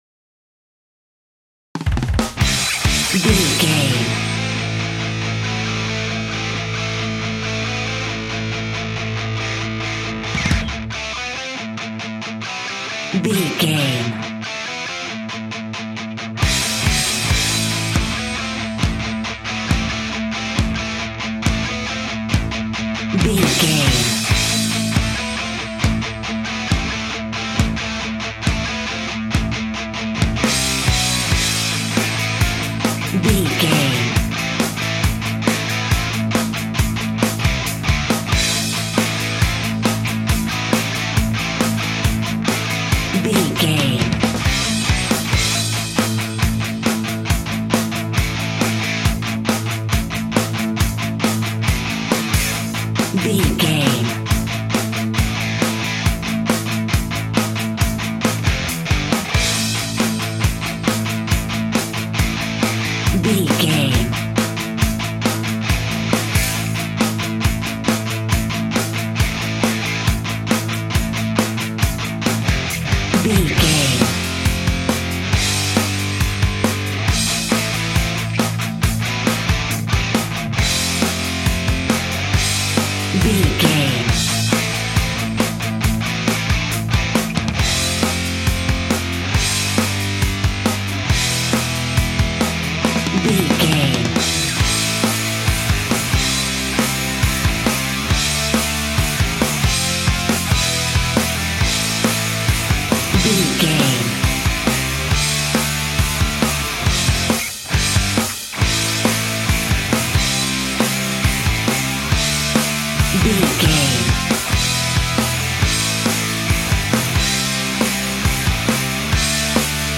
Epic / Action
Fast paced
Aeolian/Minor
hard rock
heavy metal
blues rock
distortion
rock instrumentals
Rock Bass
heavy drums
distorted guitars
hammond organ